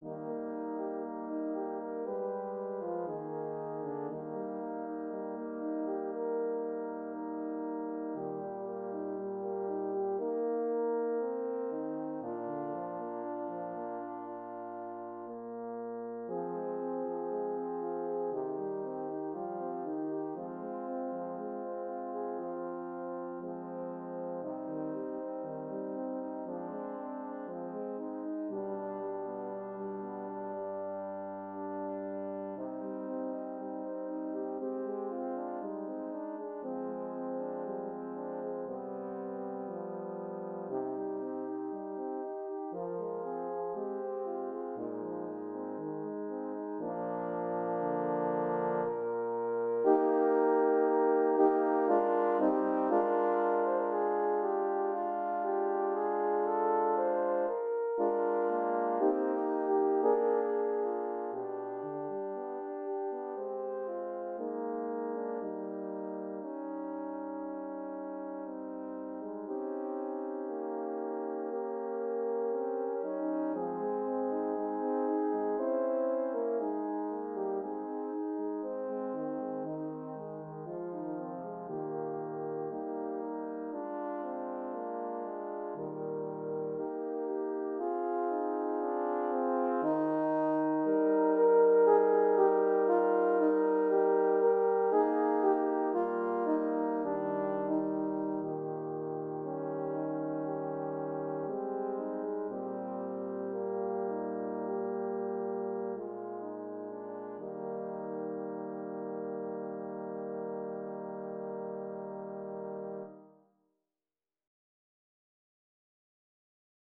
Intermezzo
Per quartetto di Corni